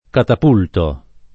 catapultare v.; catapulto [ katap 2 lto ]